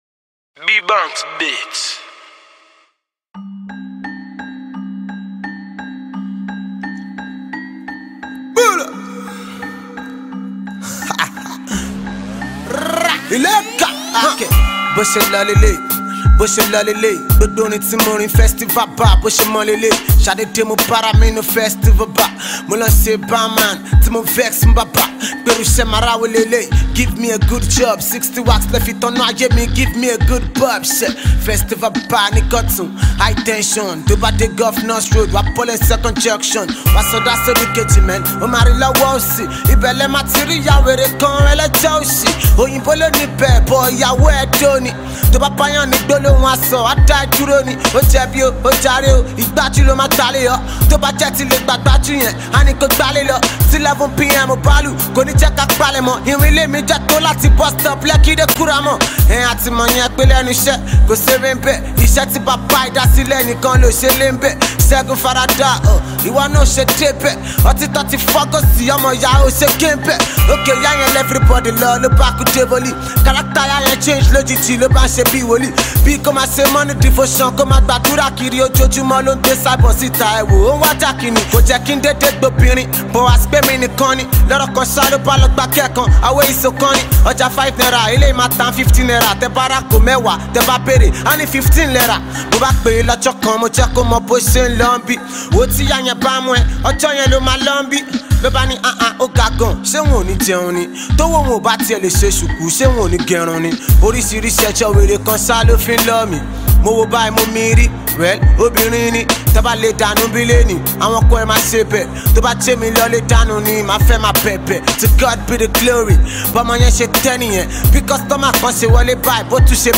Nigerian talented rapper